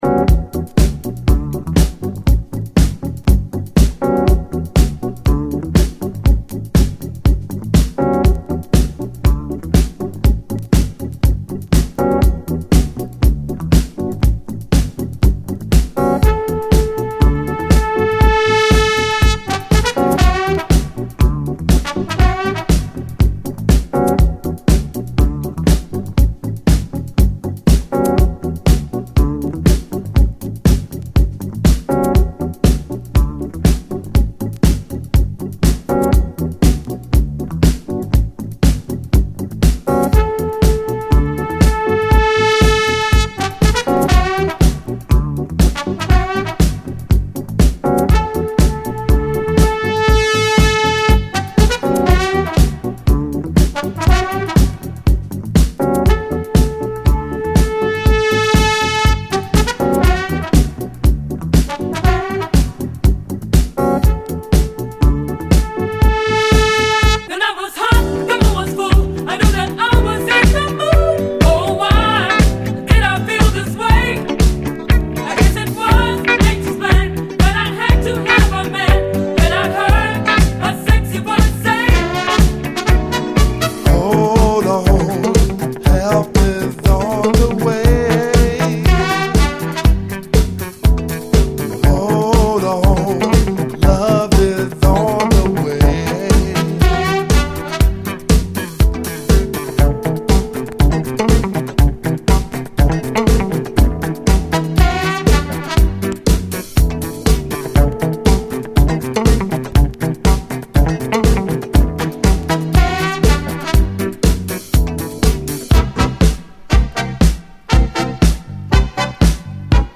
（シンセ＆女性コーラス隊が主役より目立ってますが、、）。